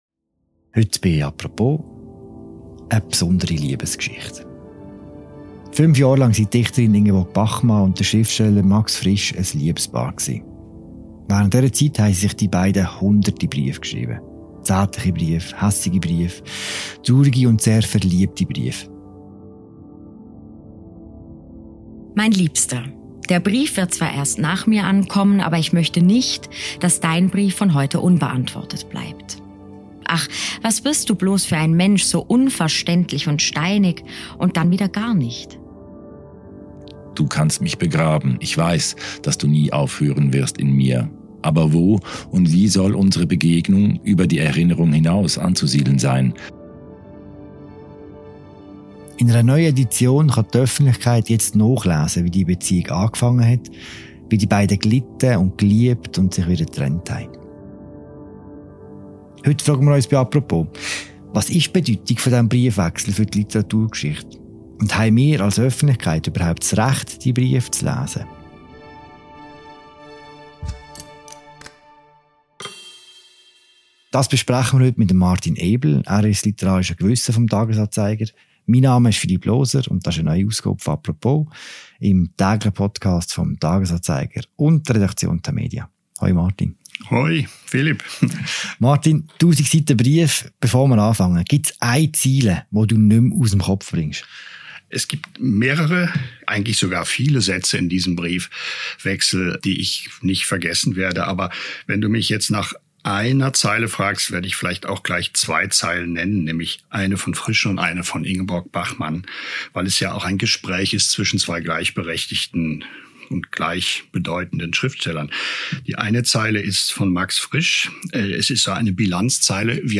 Für eine akustische, szenische Untermalung der Folge wurden die zwei Briefe in Passagen aufgeteilt und neu montiert.